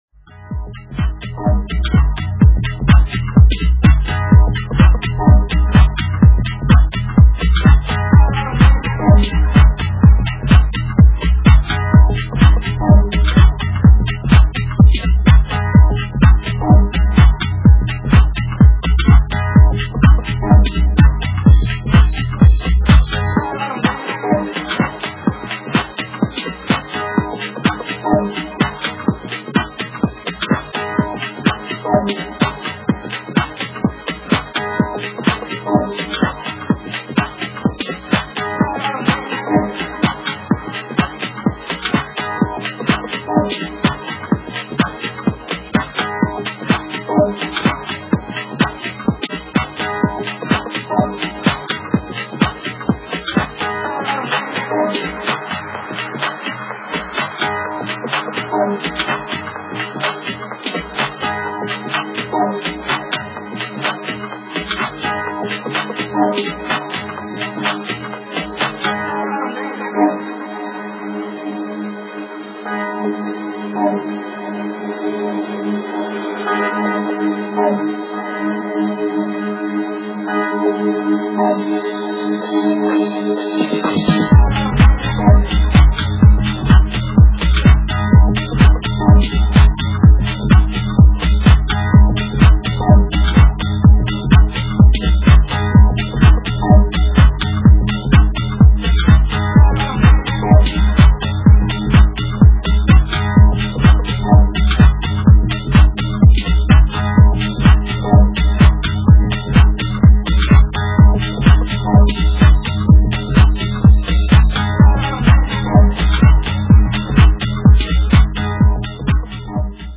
deep & grooving style